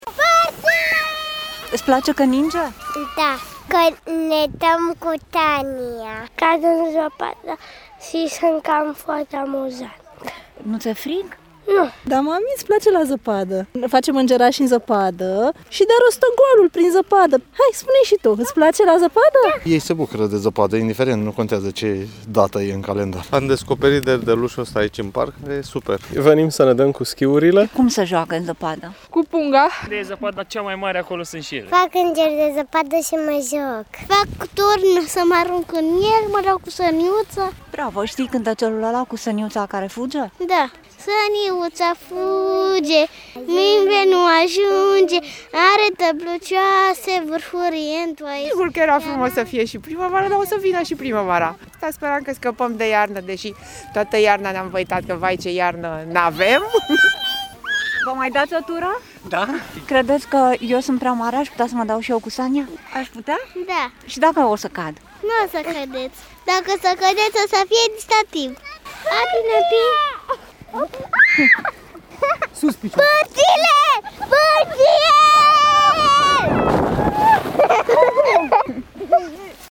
Cu sania nu rămâi înzăpezit! – reportaj AUDIO la derdeluș
În parcul IOR din sectorul 3 al Capitalei sunt 2 derdelușuri vestite în tot cartierul.